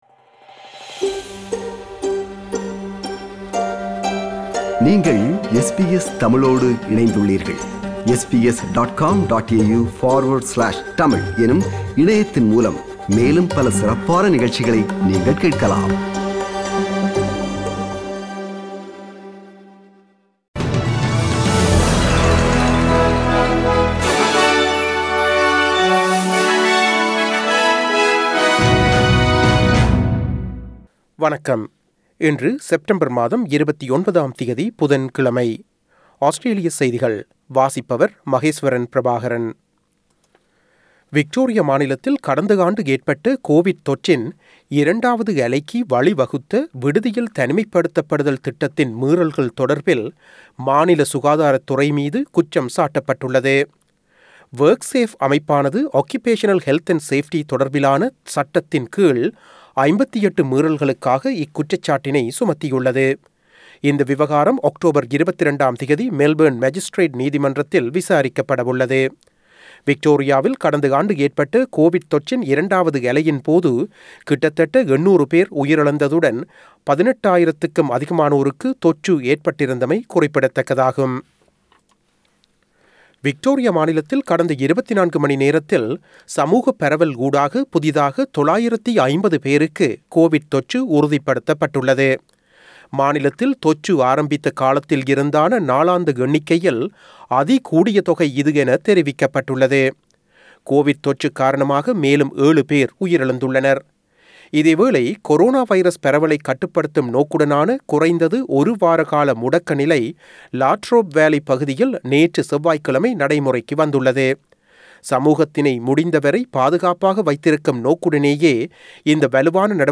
Australian news bulletin for Wednesday 29 September 2021.